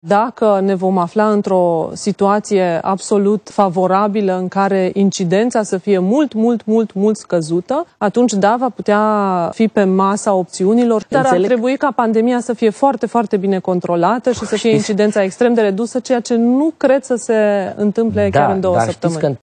Într-un interviu la televiziunea publică, Ioana Mihăilă a spus că nu crede că termenul este posibil să fie atins: